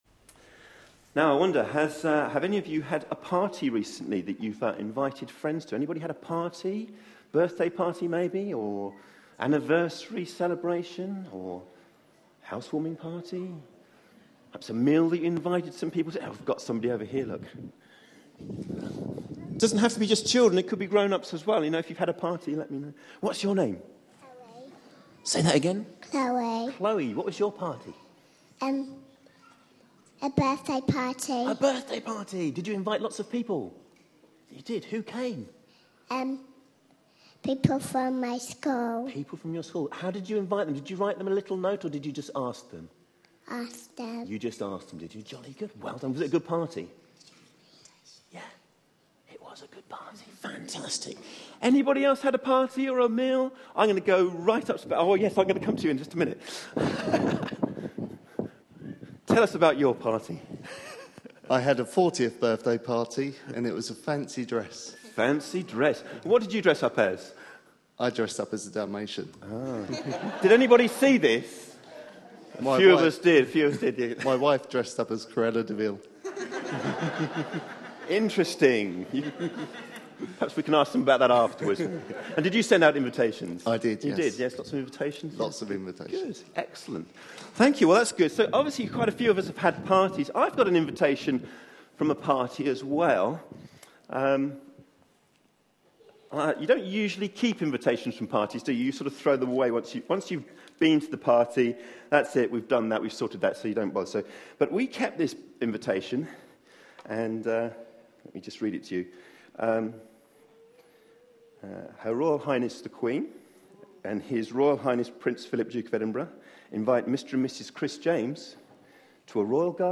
A sermon preached on 15th September, 2013.